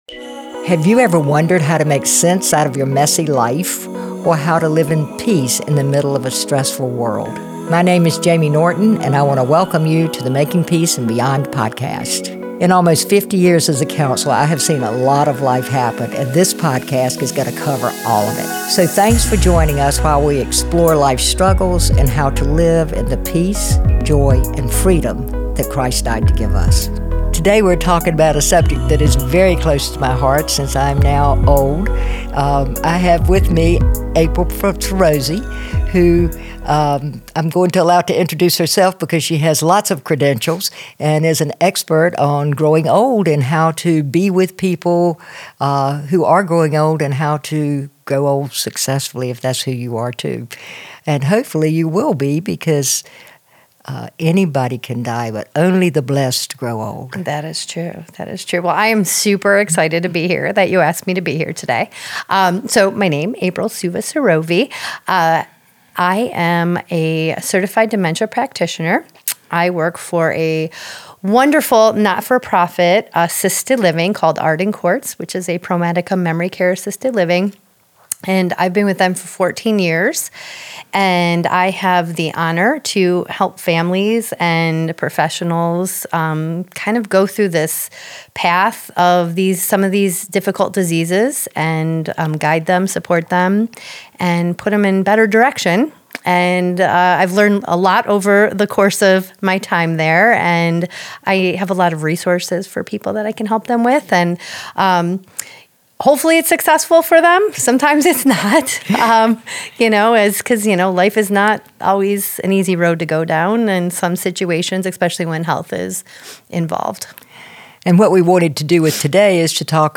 Thanks for joining us for this enlightening conversation that aims to bring peace, joy, and freedom to all stages of life.